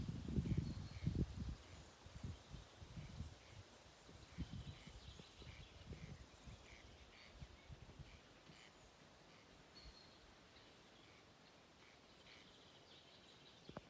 Birds -> Rails ->
Corn Crake, Crex crex
StatusSinging male in breeding season